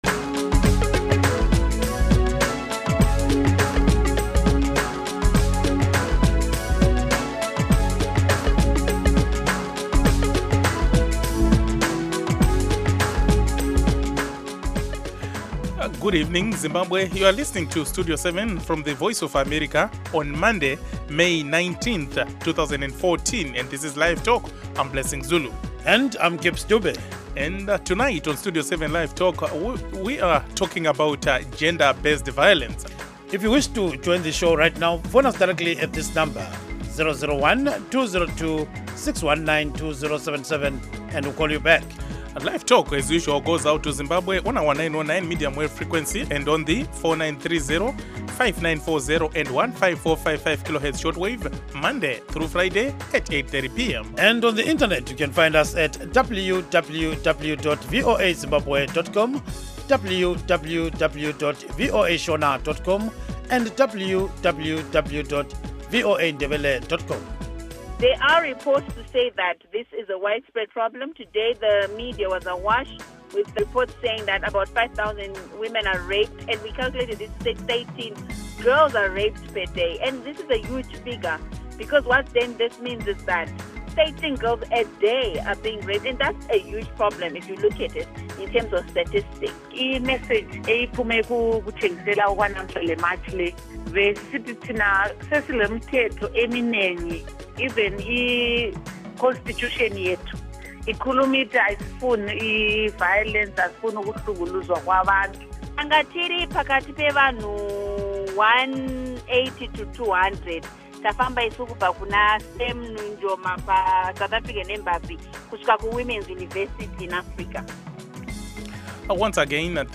Zimbabweans living outside the country who cannot receive our broadcast signals can now listen to and participate in LiveTalk in real time.